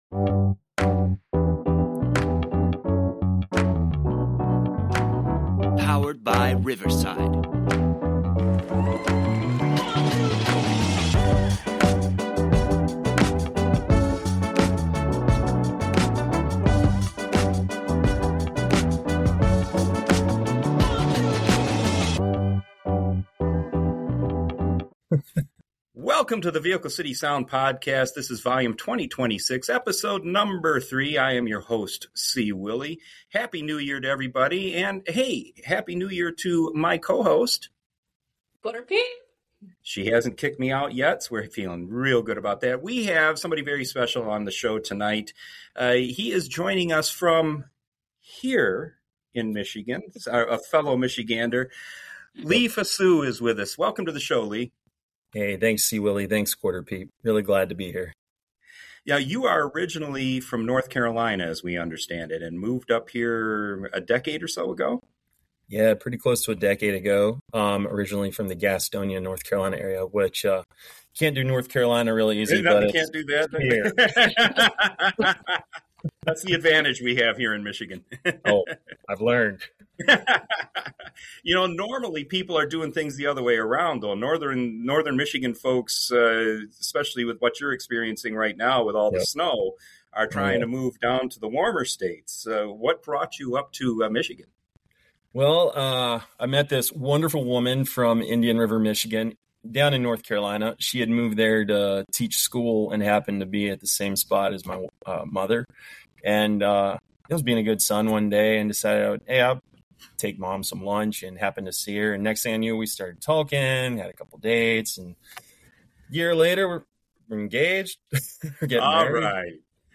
genuine country sound